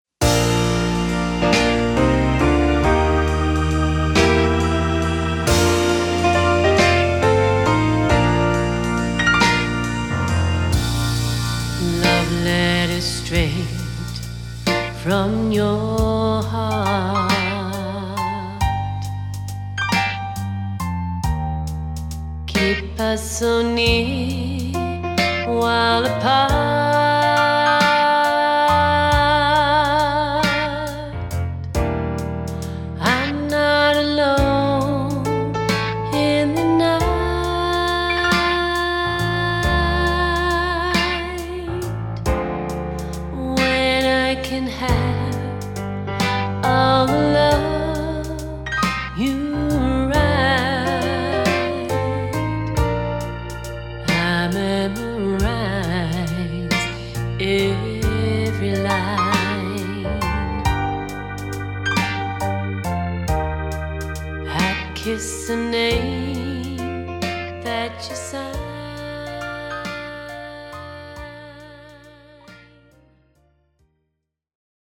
CLASSIC SONG COVERS
A lovely mix of voices.
Backing Vocals…
(The Cottage studio)
(The Edge studio)